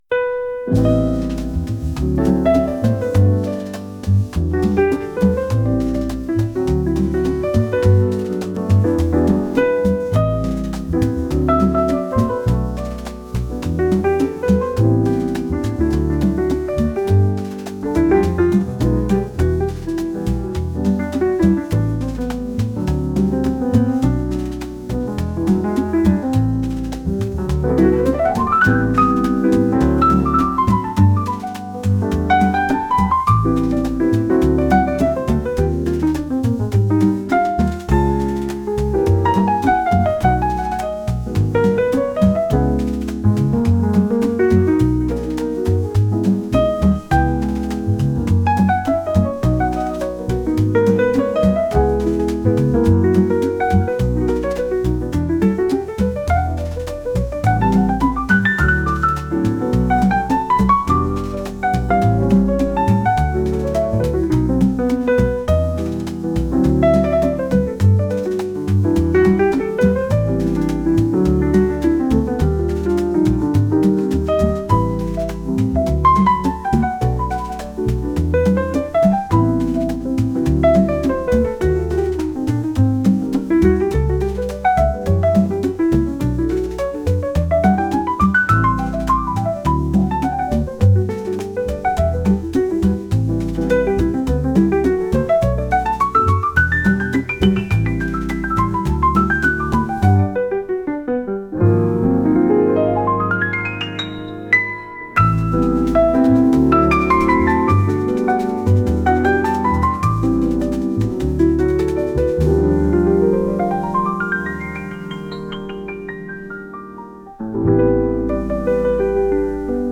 ピアノメインのジャズ曲です。